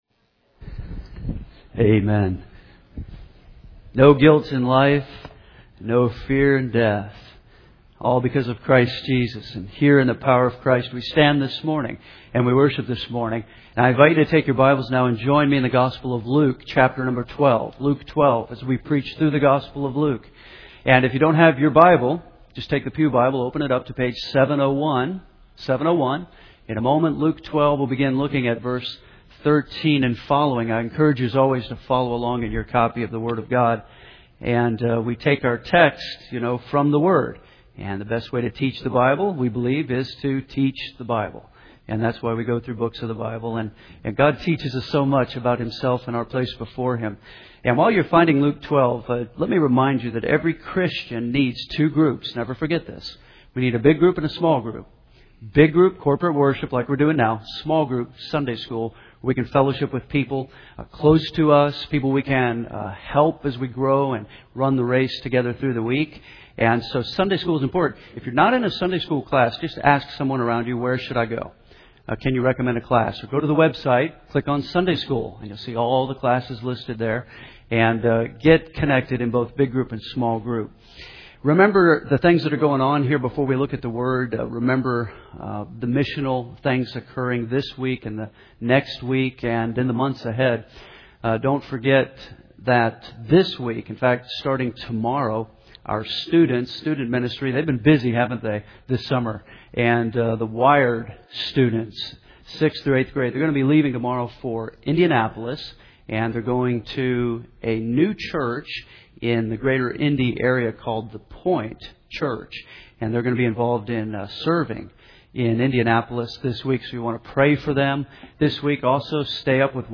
Henderson’s First Baptist Church, Henderson